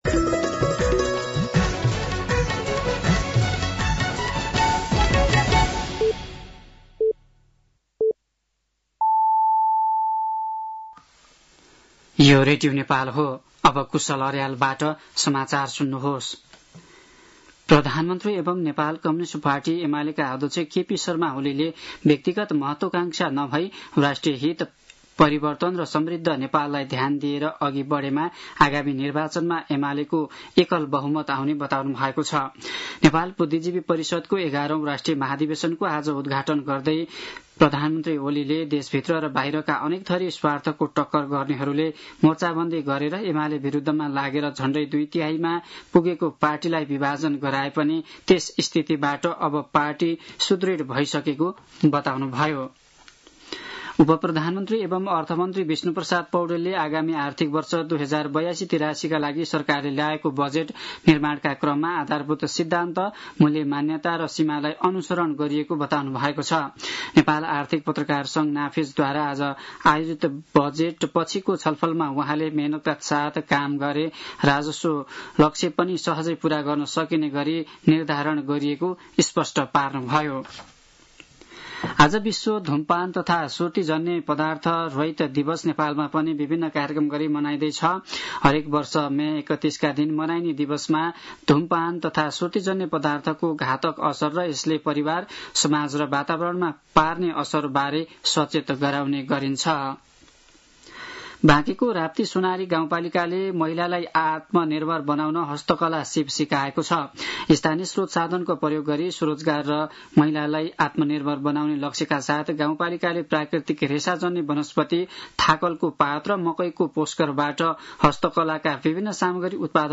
साँझ ५ बजेको नेपाली समाचार : १७ जेठ , २०८२
5.-pm-nepali-news-1-3.mp3